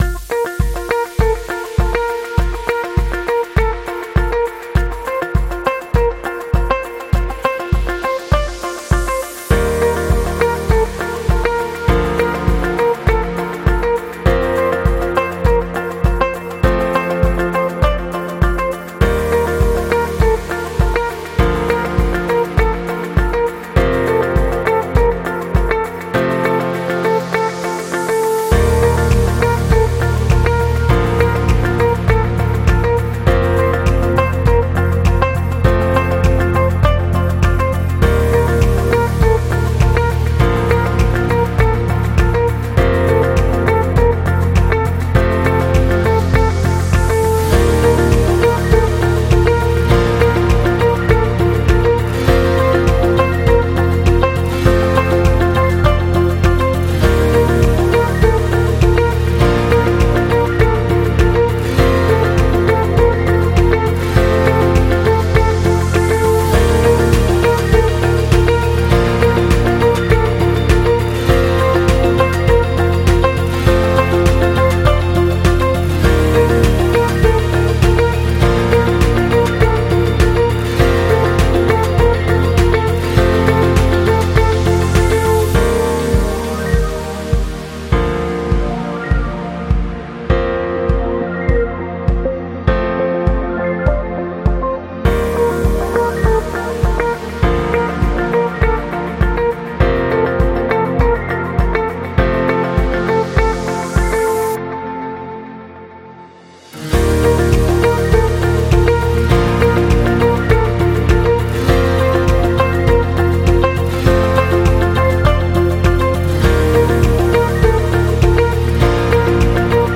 Corporate